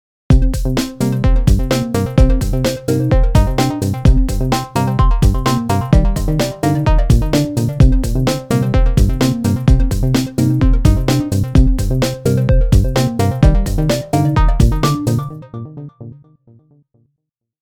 This one's a bit slow.